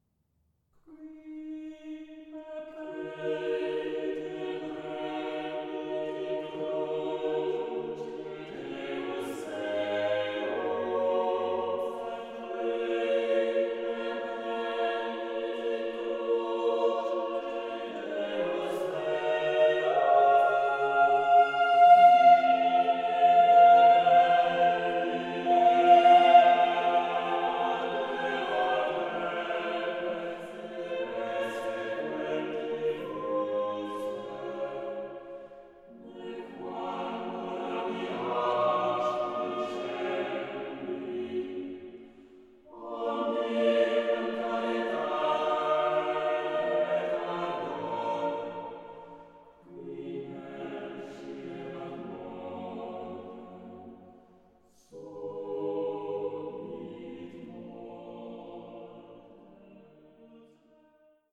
soprano
tenors
organ
sounding revelatory as choral works in sacred guise.